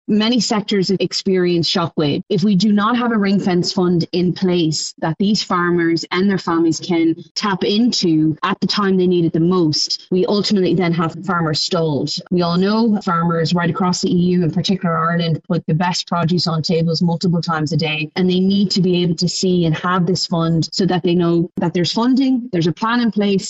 Fine Gael MEP Maria Walsh says increased EU funding is essential to assist farming families in planning for a crisis.